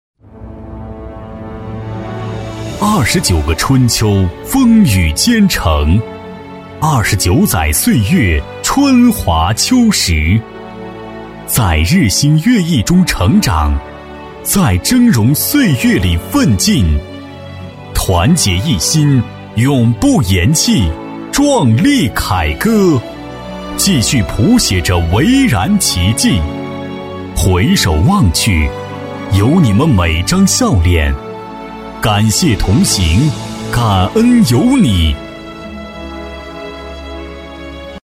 男B44-汇报片配音-大气昂扬
男B44-汇报片配音-大气昂扬.mp3